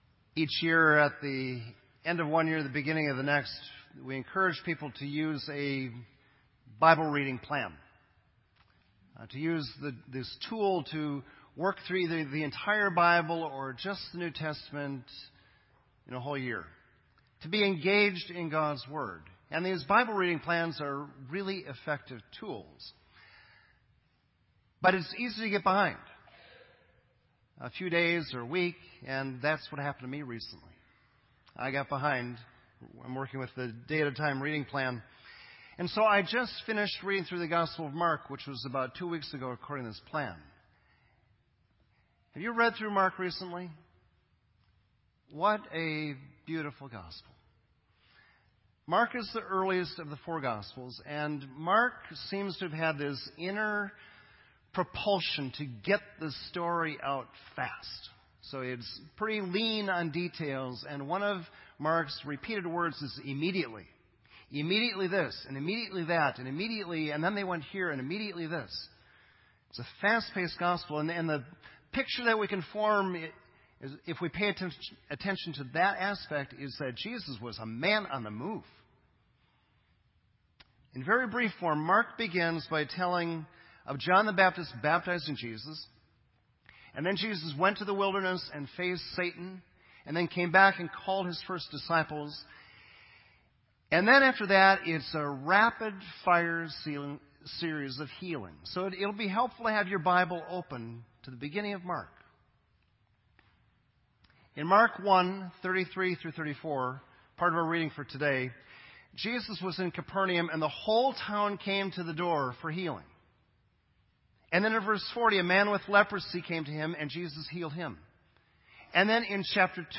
This entry was posted in Sermon Audio on February 9